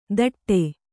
♪ taṭṭe